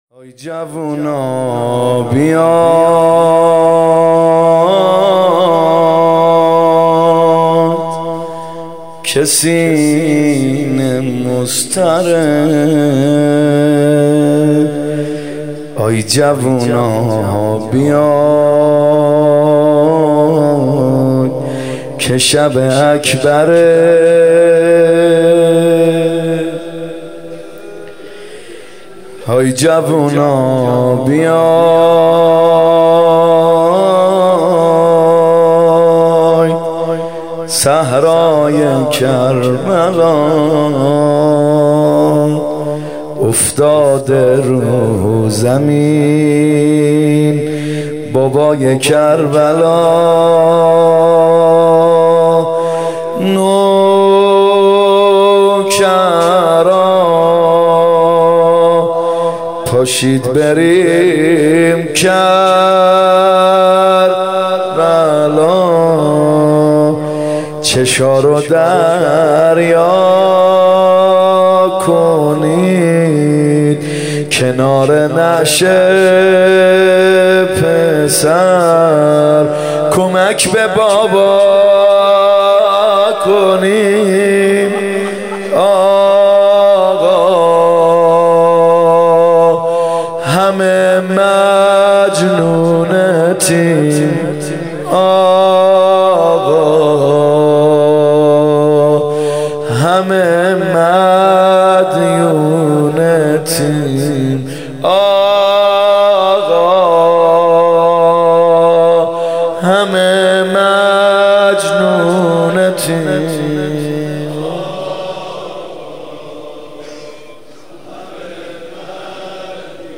محرم 95 شب هشتم زمزمه
محرم 95(هیات یا مهدی عج)